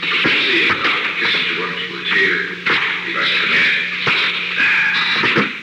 Secret White House Tapes
Conversation No. 638-3
Location: Oval Office
The President met with an unknown person.